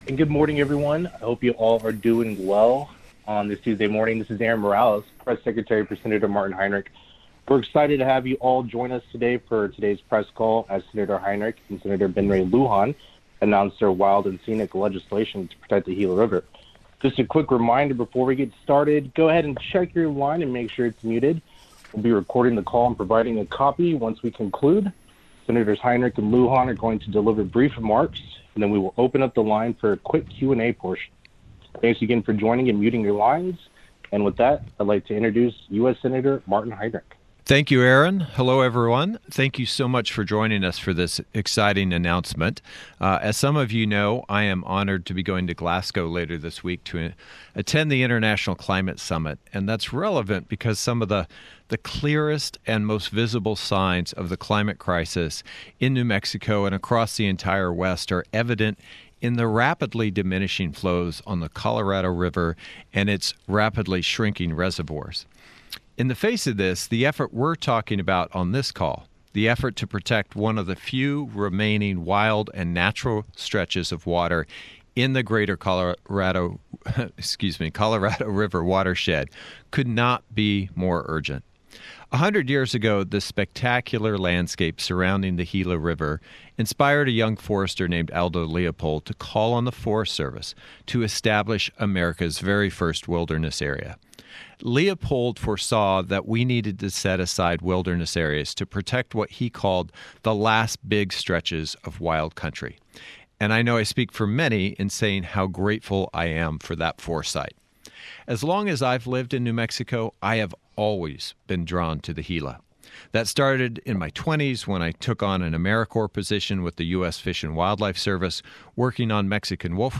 Senators Heinrich and Luján held a press call today announcing the legislation.